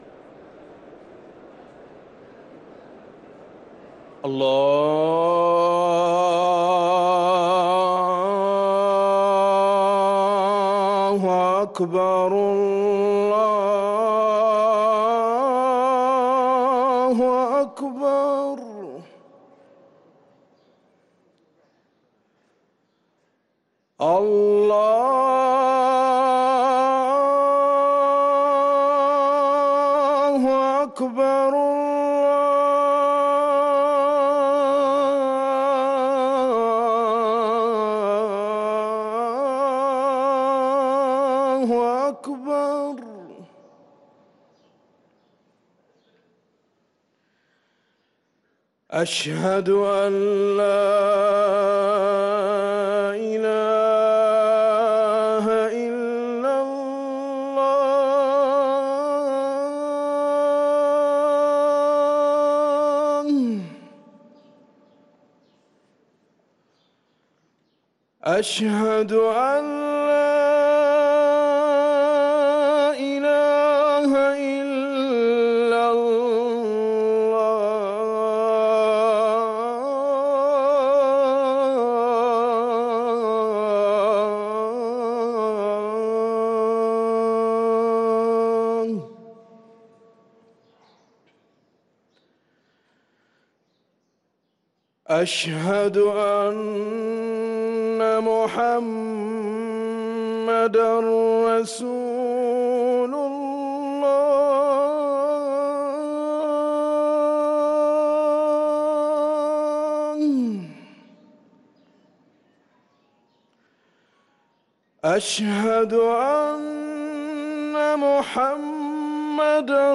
أذان العصر للمؤذن
ركن الأذان